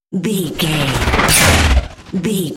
Whoosh to hit sci fi
Sound Effects
futuristic
intense
woosh to hit